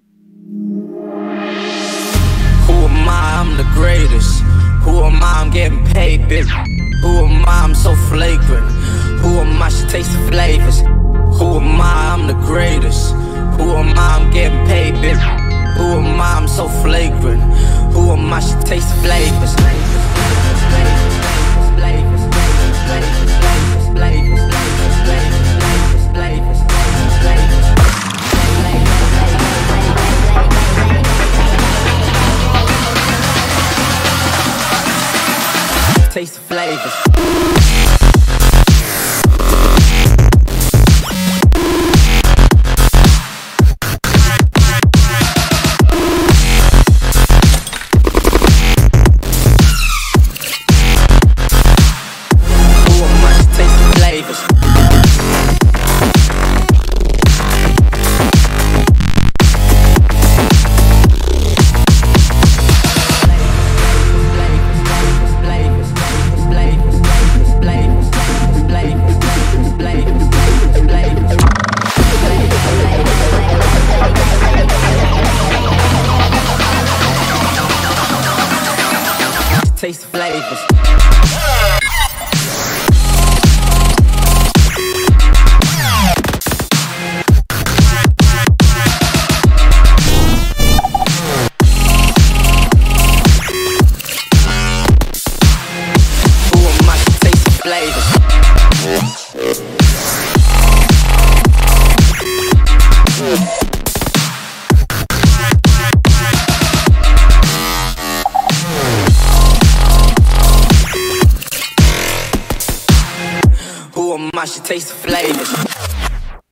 BPM110
MP3 QualityMusic Cut